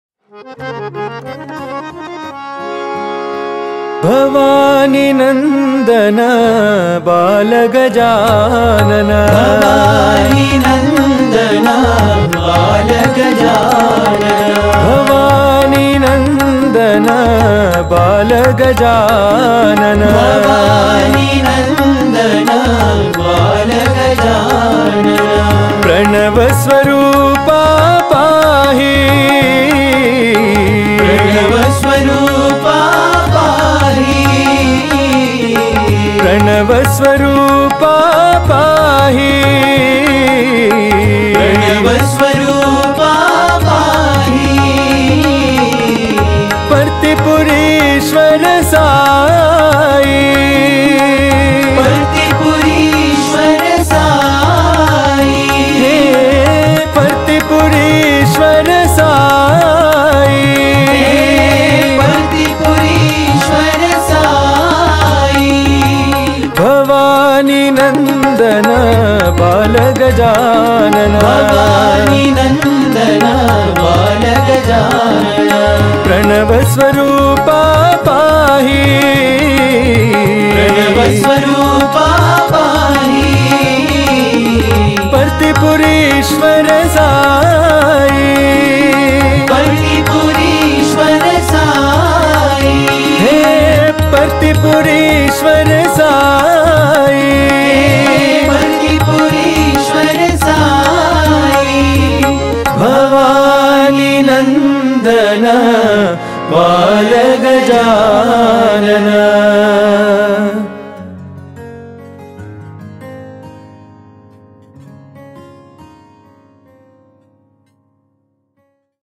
Home | Bhajan | Bhajans on various Deities | Ganesh Bhajans | 05 Bhavani Nandana Bala Gajanana